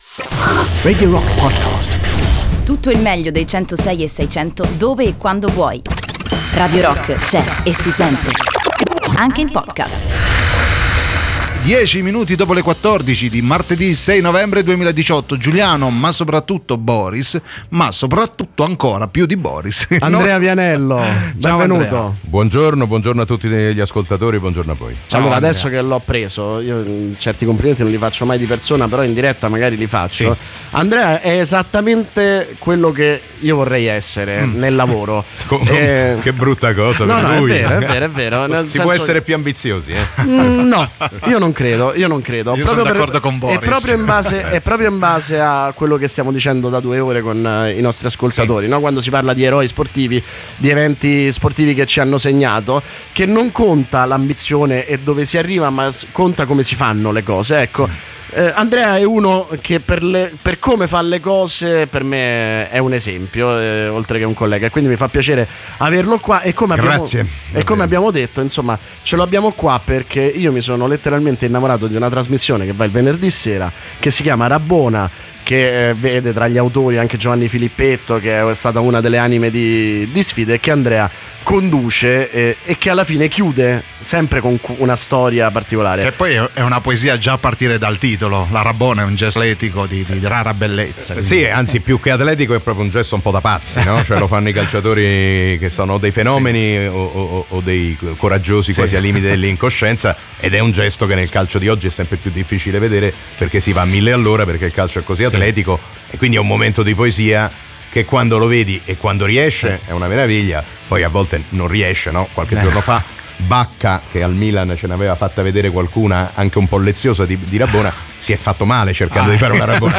Andrea Vianello, conduttore e giornalista tv negli studi di Radio Rock durante "NON CI RESTA CHE IL ROCK"